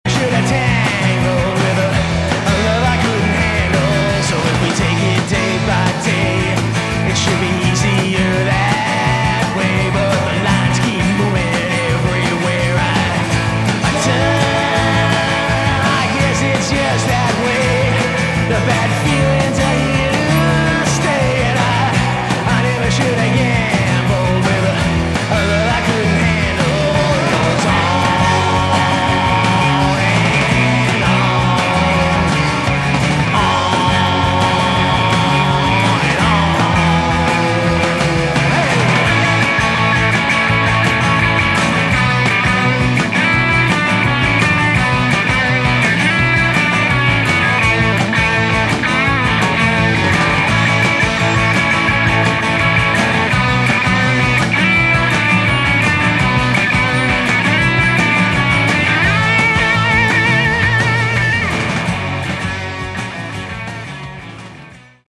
Category: Hard ROck
bass
drums, percussion
backing vocals